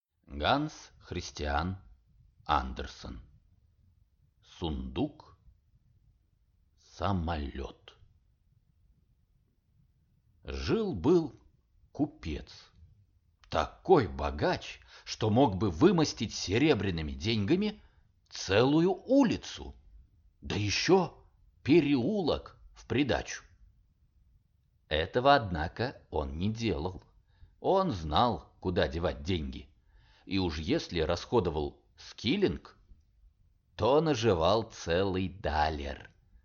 Аудиокнига Сундук-самолет | Библиотека аудиокниг